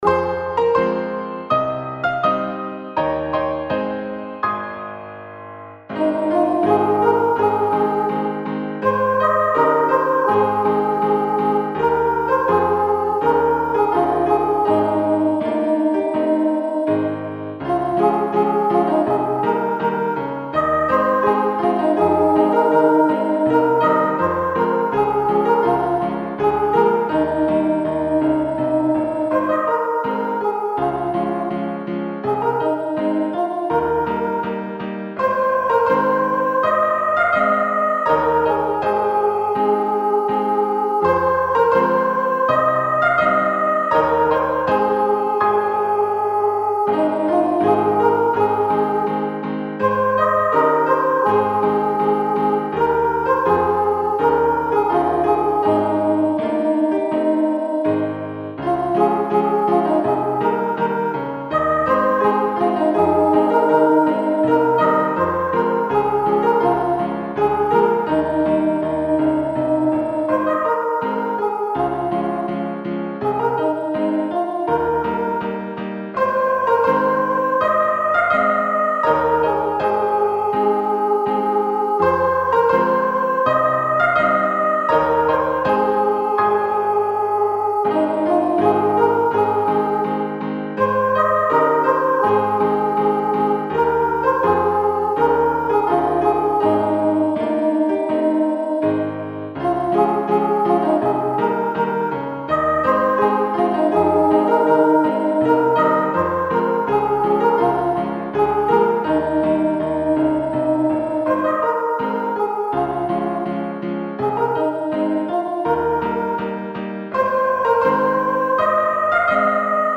國父紀念歌（合唱版本）~~~~~~~~~~~~~~~~~~~~~~~~~~~~~~~~~~~~~☆☆☆☆☆☆☆☆☆☆☆☆☆☆☆☆☆☆☆☆☆☆☆☆☆☆☆☆